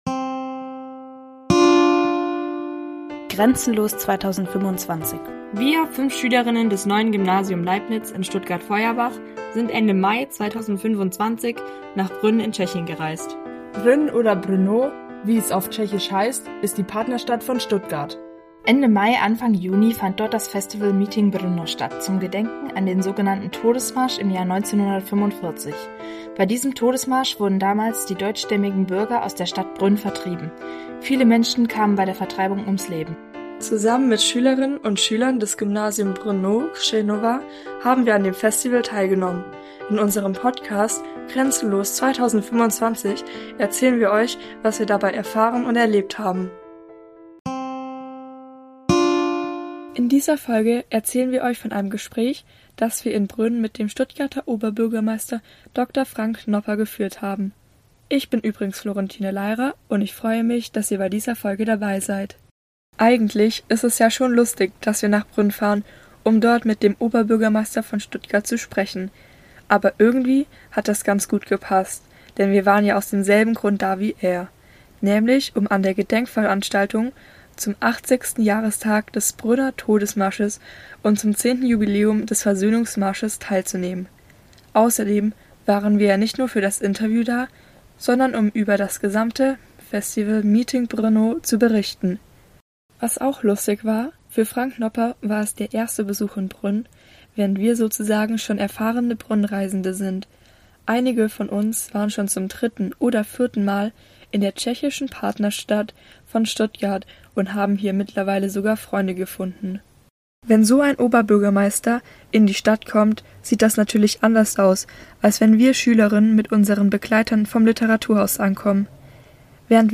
Interview mit Dr. Frank Nopper